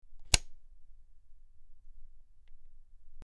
SwitchClicksOnOff PE447602
Switch; Clicks On And Off.